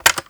BUTTON_03.wav